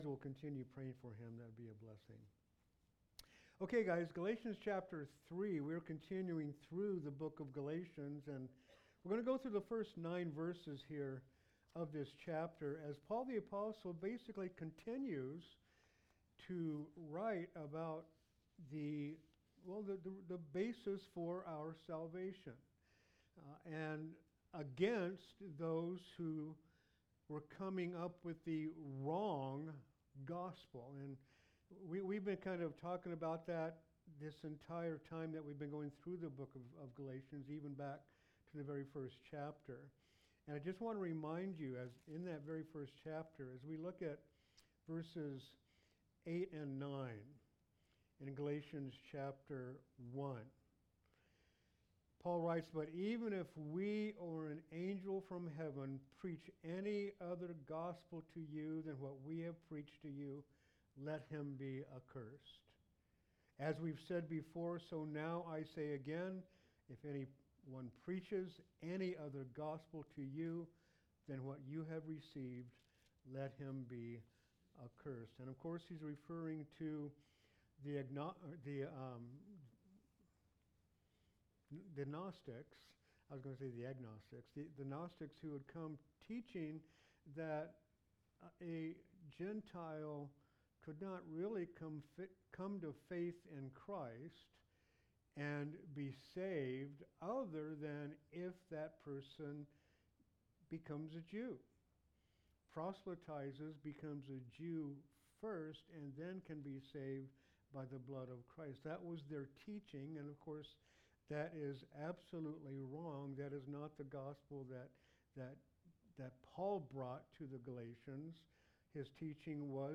Sermons
Sermons from Calvary Chapel of Upland: Upland, CA